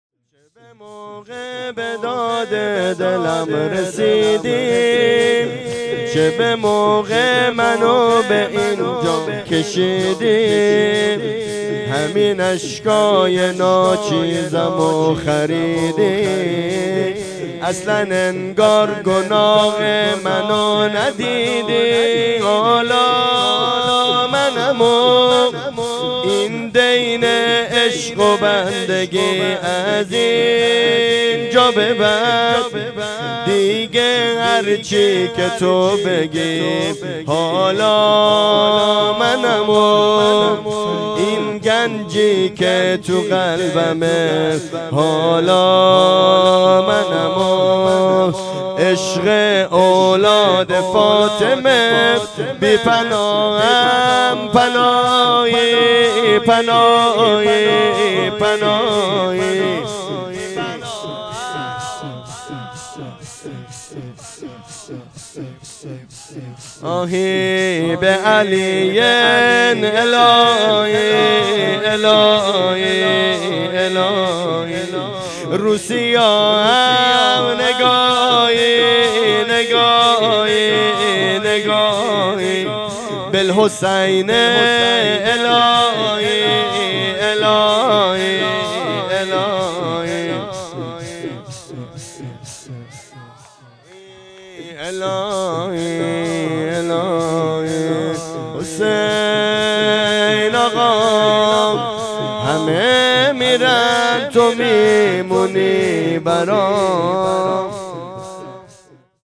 ویژه برنامه هفتگی هیئت در ماه مبارک رمضان1403